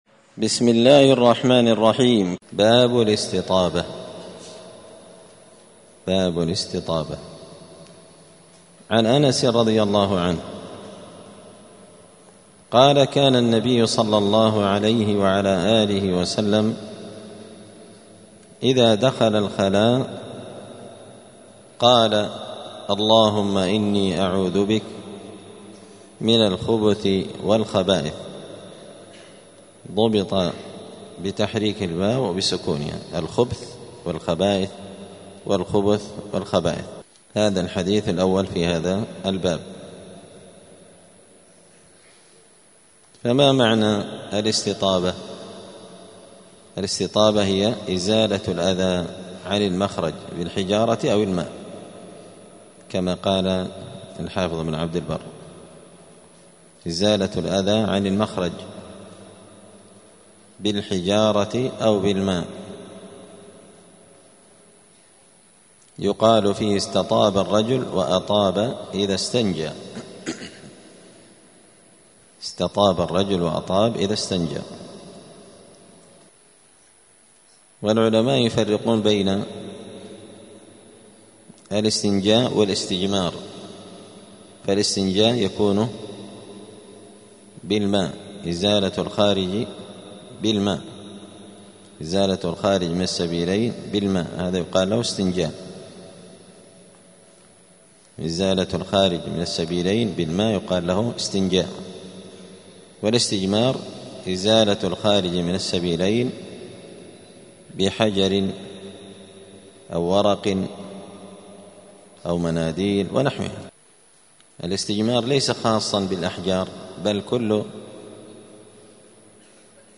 دار الحديث السلفية بمسجد الفرقان قشن المهرة اليمن
*الدرس الثاني والستون [62] {باب الاستطابة قراءة الذكر عند دخول الخلاء}*